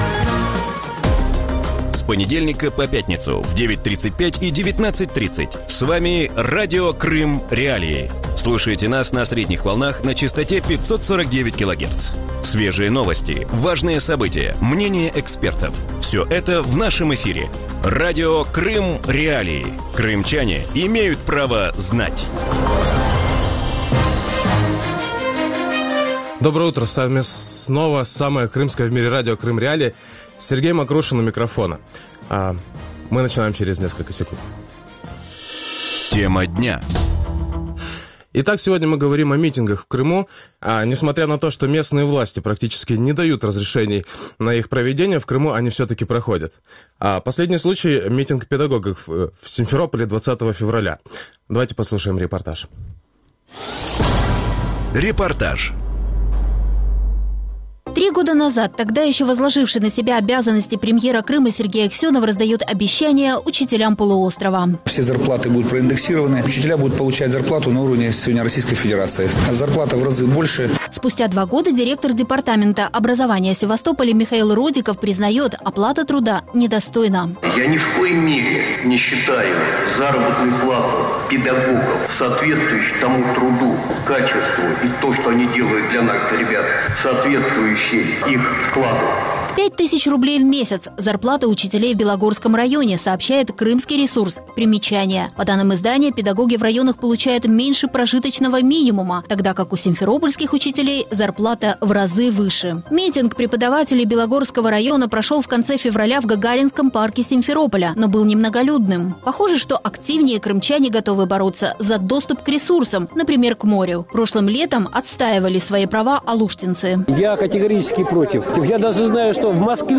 Вранці в ефірі Радіо Крим.Реалії говорять про мітинги в Криму.